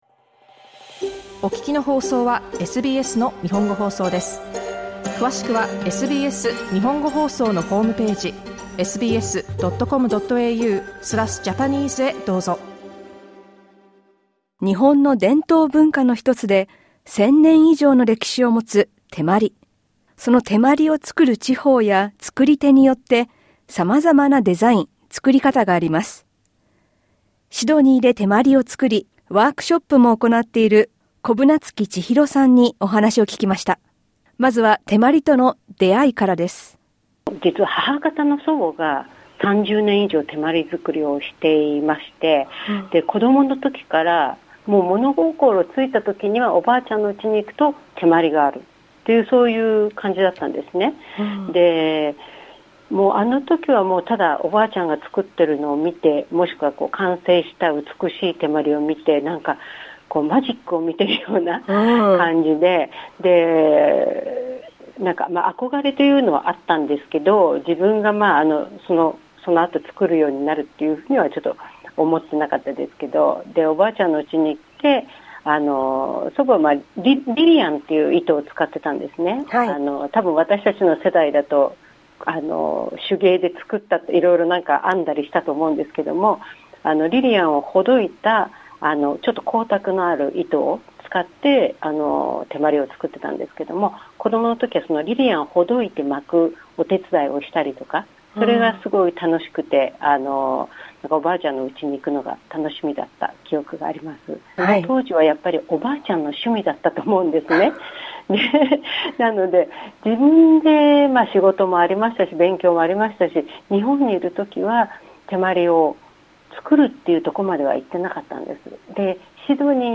インタビューでは、手まりとの出会いやそのおもしろさ、シドニーでのワークショップ、印象に残る手まりなどについて聞きました。